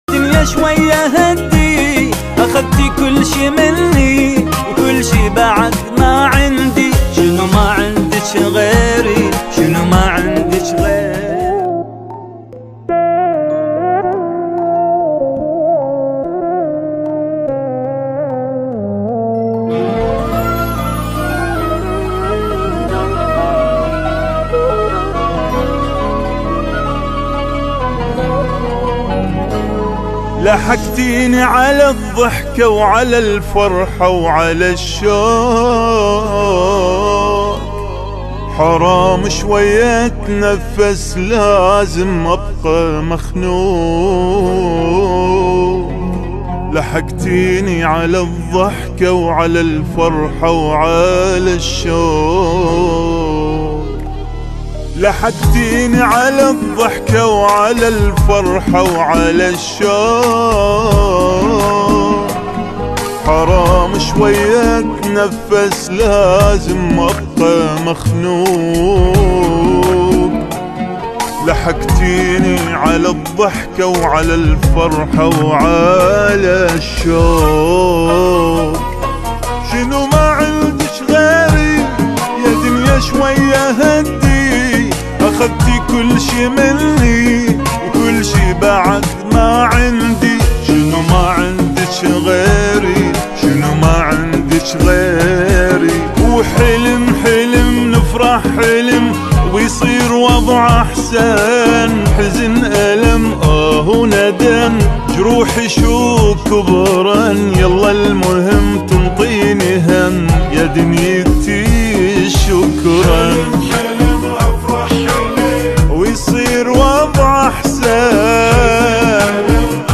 اغاني عراقيه